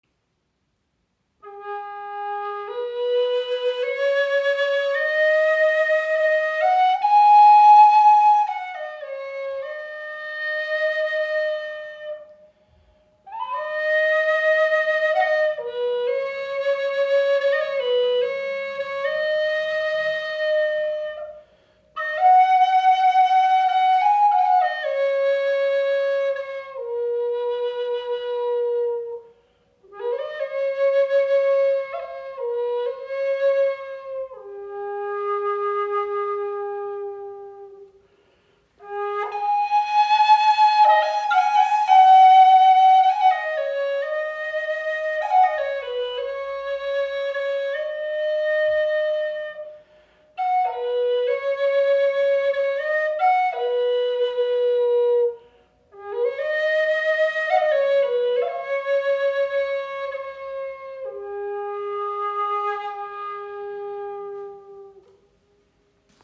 • Icon Handgefertigt aus Bubinga Holz mit Rosenholz Adler Windblock
Warmer, klarer Klang – ideal für Meditation, Klangarbeit und spirituelle Praxis.
Die präzise Intonation, das weiche Ansprechverhalten und der volle Klang machen sie zu einem treuen Begleiter auf jeder musikalischen und spirituellen Reise.